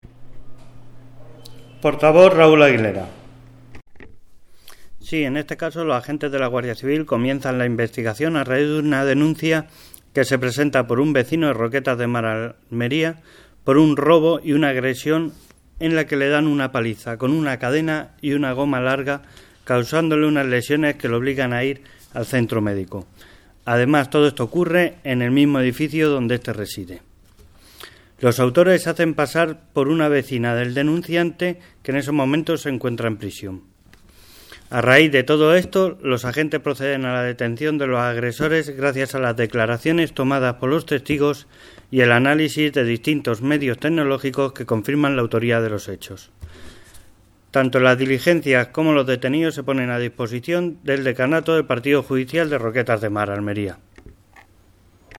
Declaraciones: